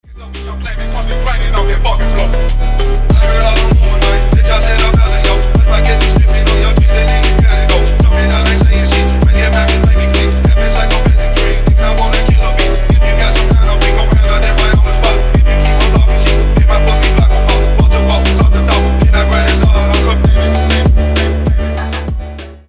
• Качество: 320, Stereo
мелодичные
качающие
фонк